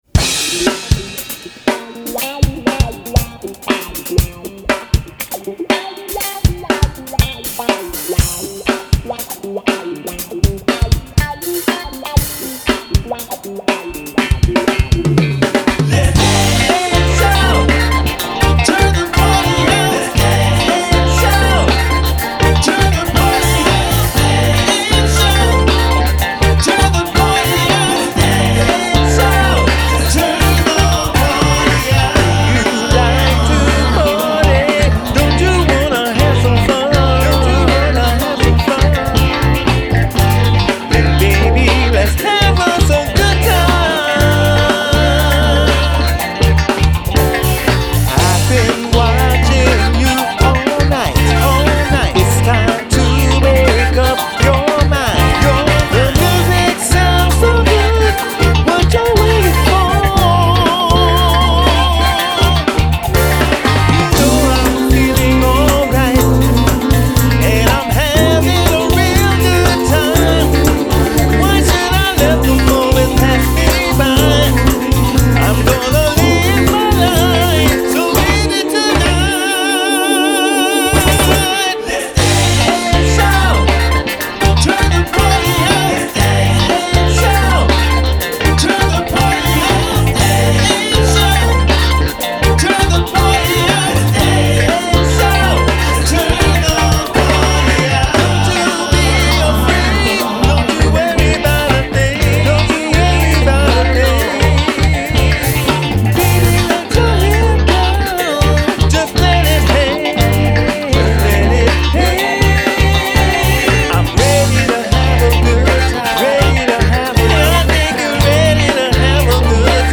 Home > Music > Rnb > Bright > Laid Back > Running